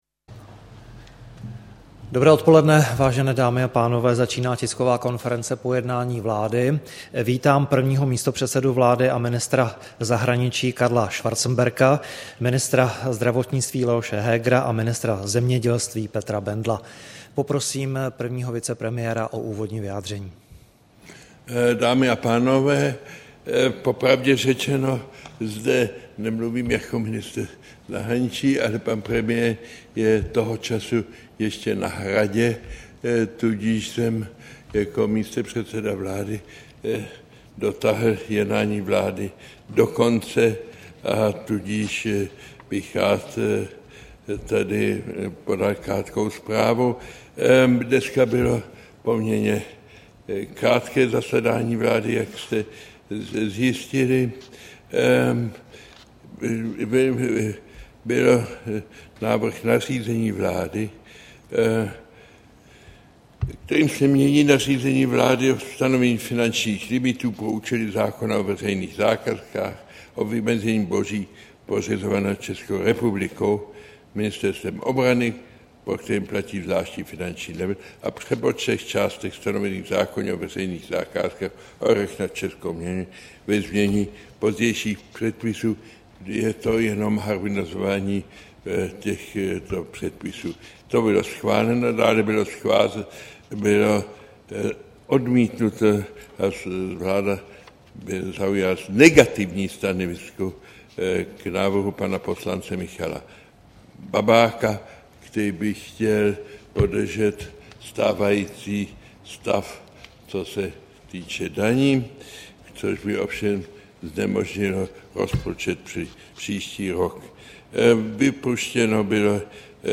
Tisková konference po jednání vlády, 31. října 2012